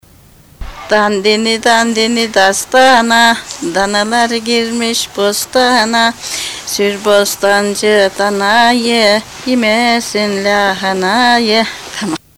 Anatóliai Népdalok I-II.
İçel. Mut. Dağpazarı, 1989.9.